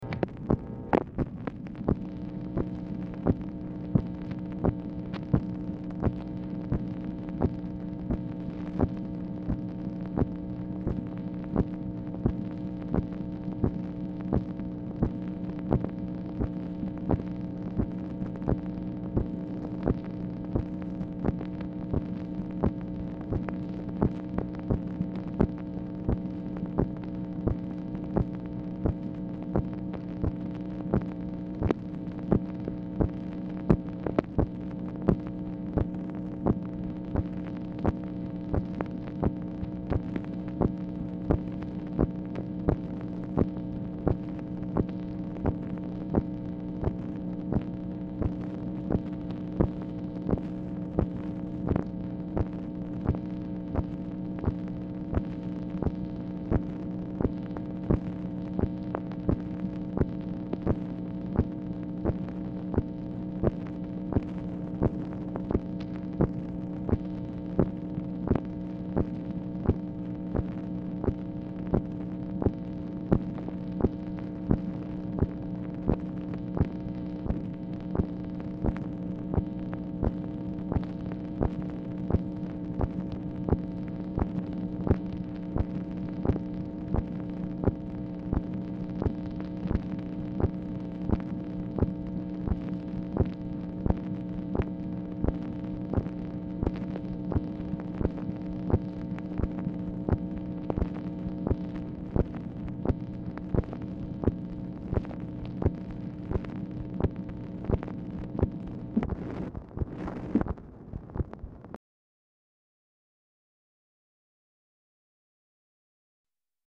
Format Dictation belt
Location Of Speaker 1 Oval Office or unknown location
White House Telephone Recordings and Transcripts Speaker 2 MACHINE NOISE